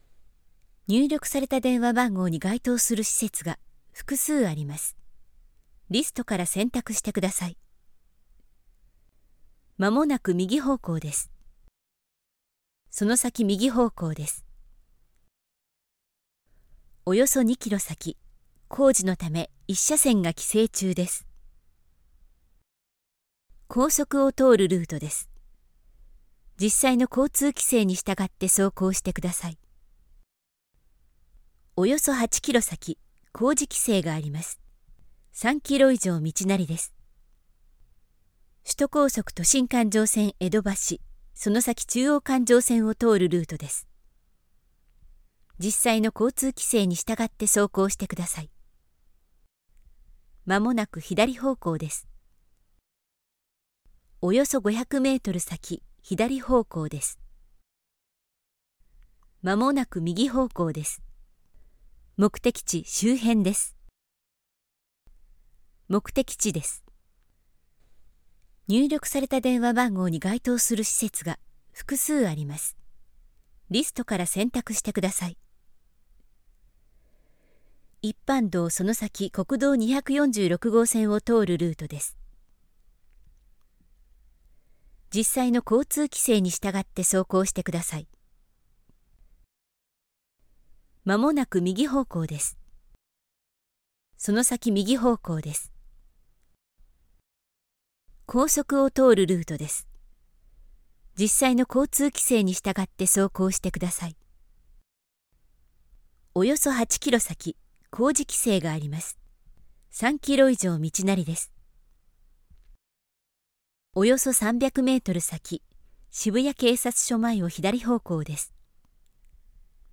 Comercial, Versátil, Seguro, Empresarial, Joven
Corporativo